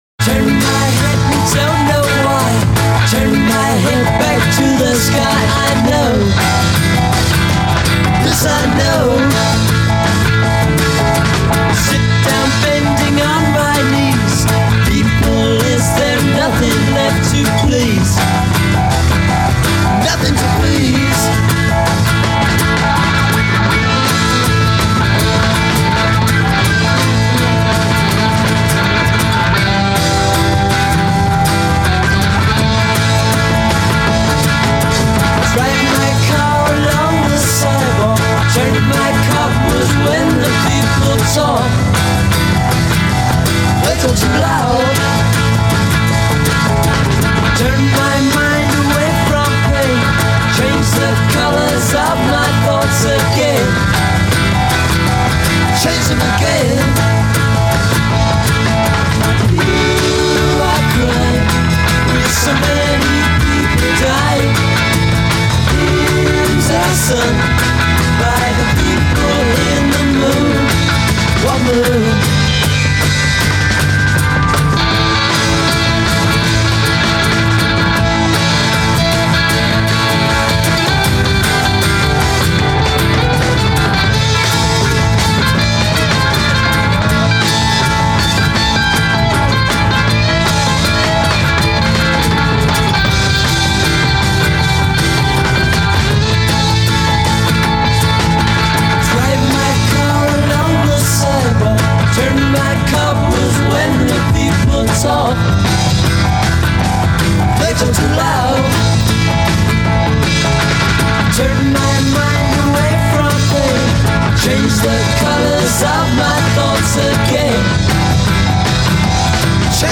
fell under the spell of Psychedelia.